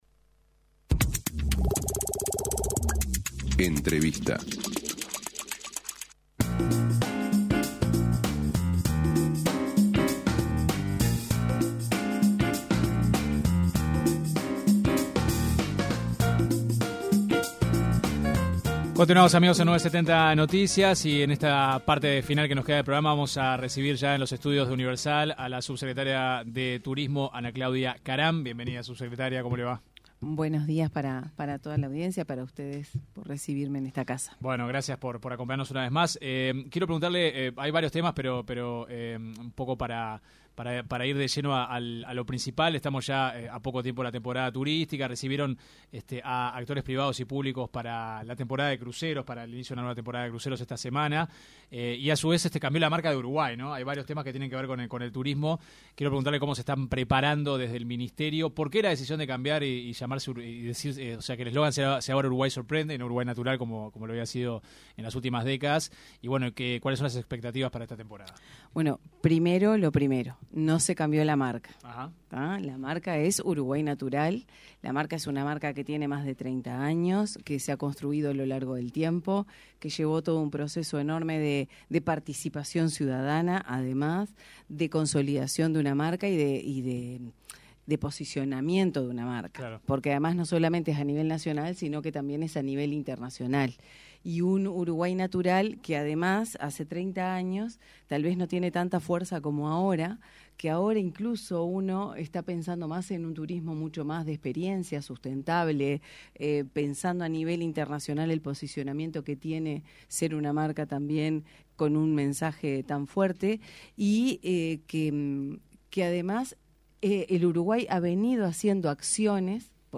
La subsecretaria del Ministerio de Turismo, Ana Claudia Caram en entrevista con 970 Noticias dijo que la marca Uruguay Natural “no se cambió. Tiene 30 años, llevó un proceso enorme de participación ciudadana y posicionamiento de una marca a nivel nacional e internacional”.